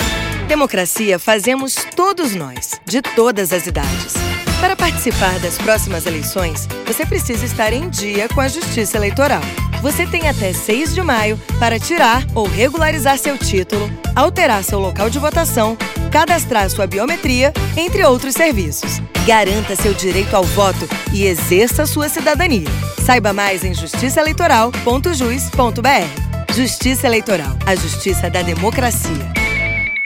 Campanha Prazo Final - Spot